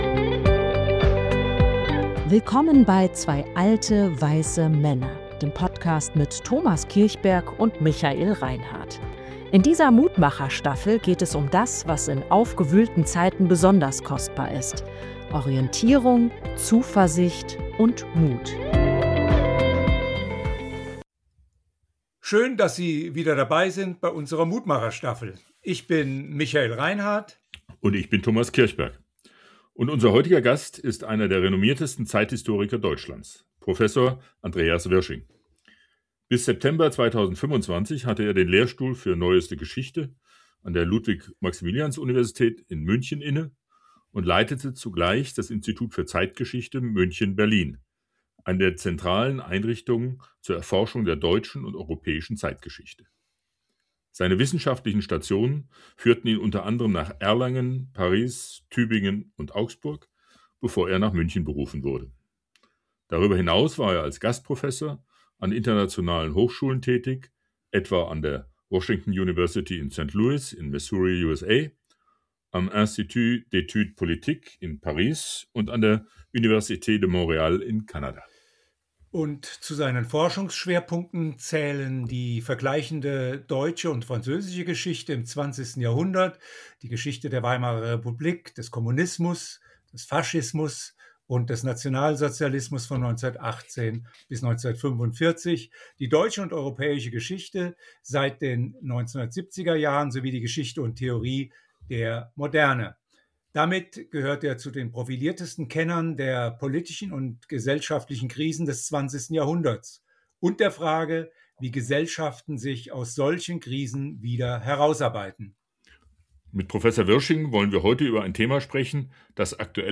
In dieser Folge von „2 alte wei(s)se Männer“ sprechen wir mit Professor Andreas Wirsching, einem der renommiertesten Zeithistoriker Deutschlands.